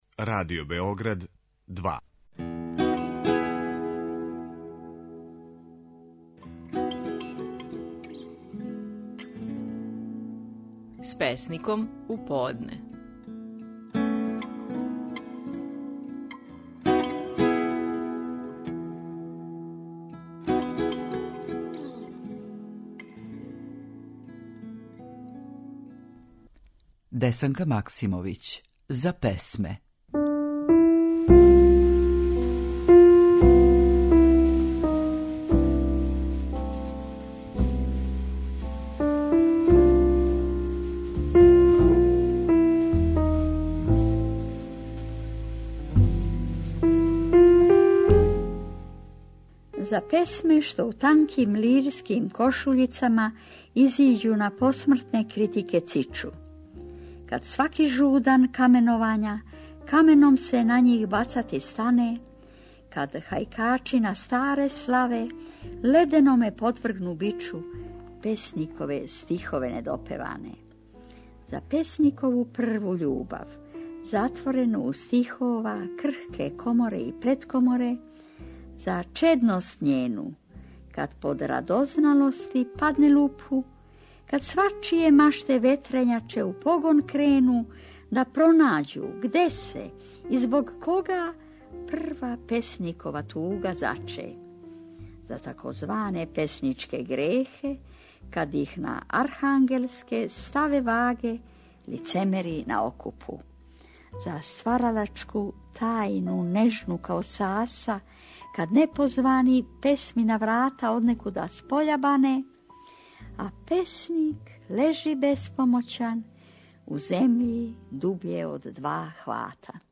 Наши најпознатији песници говоре своје стихове
Десанка Максимовић говори: "За песме".